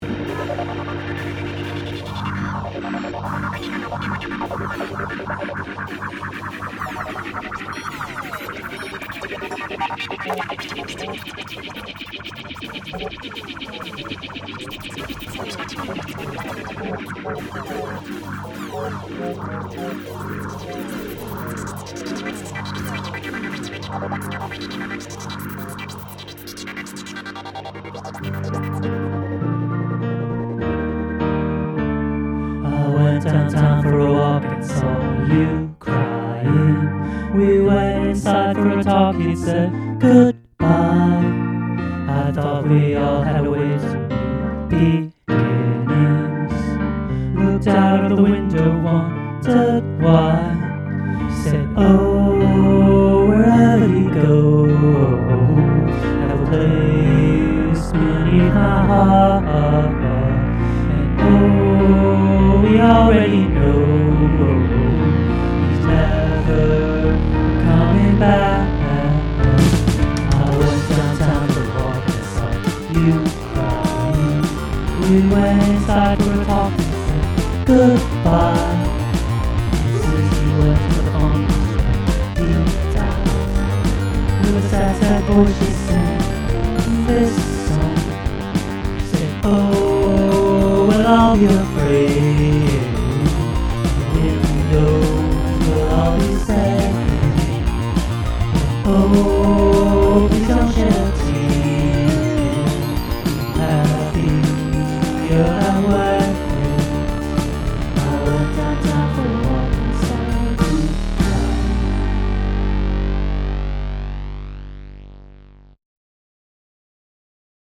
On this version I play all instruments and sing all parts.